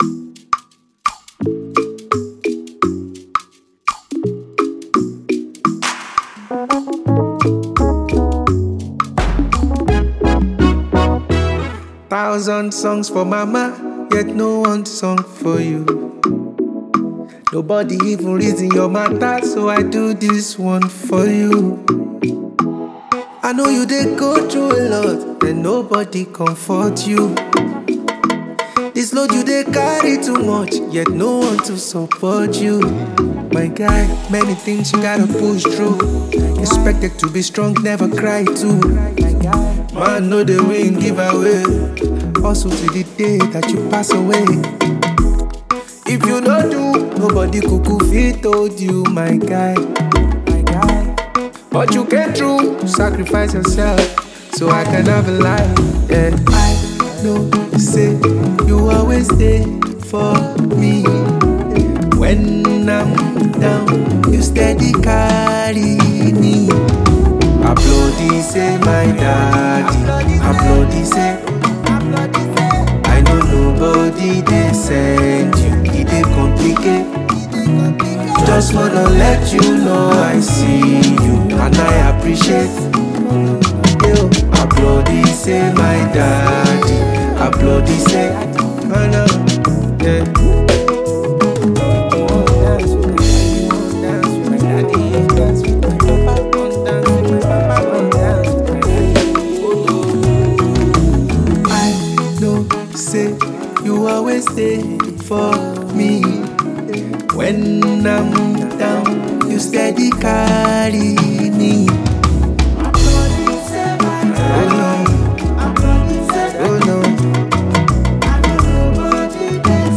a creative Nigerian singer